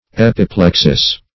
Epiplexis \Ep`i*plex"is\, n. [L., reproof, fr. Gr.